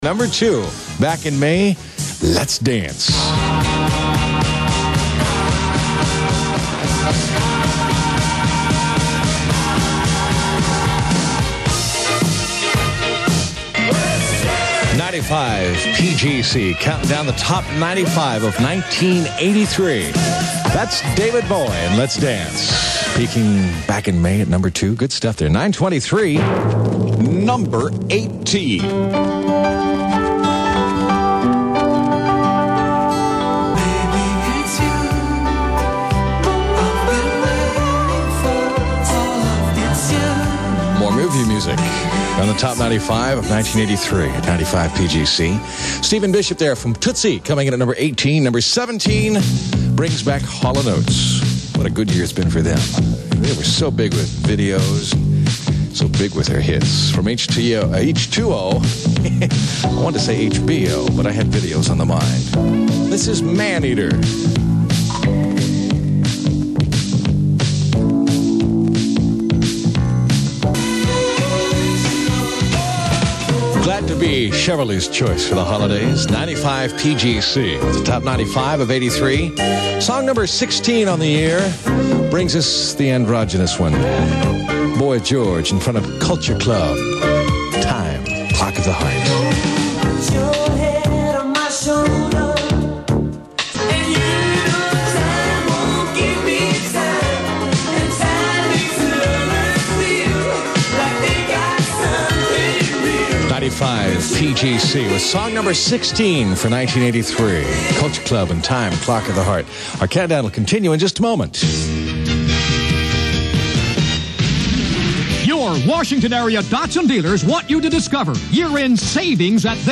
The original source of this tape is unknown but reception varies from good to so-so.
Jingles are from a TM package produced in 1980, 'Radio Express' and were used until a new locally produced package for the station was created by a studio in Rockville in 1984.